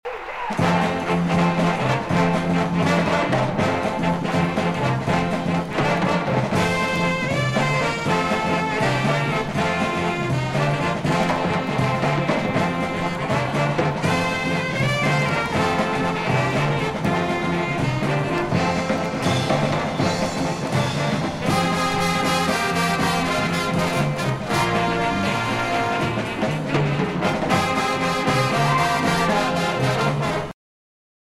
January 9-10, 1970 marked its 20th concert.
trumpet
trombone
alto saxophone
baritone saxophone
piano
percussion
Jazz vocals